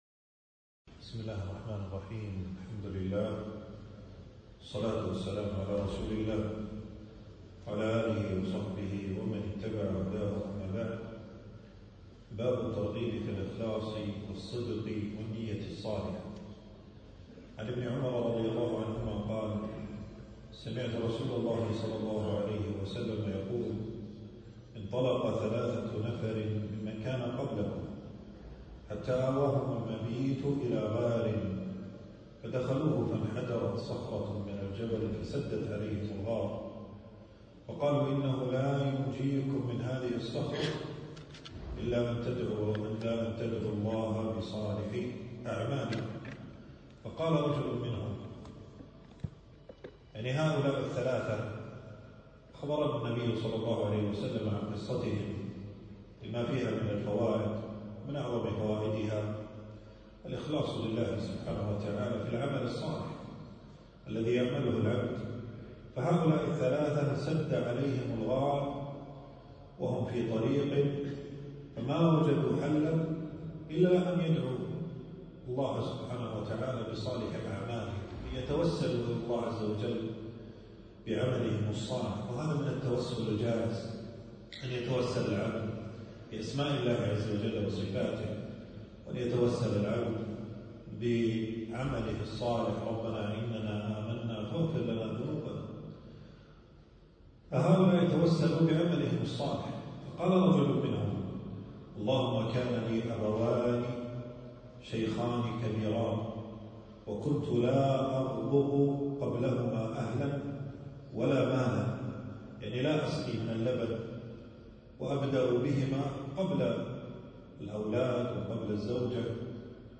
في مسجد أبي سلمة بن عبدالرحمن.